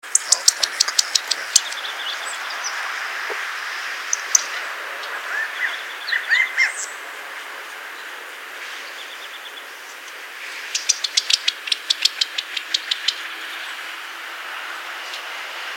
Codorniz – Universidad Católica de Temuco
Codorniz-Callipepla-californica.mp3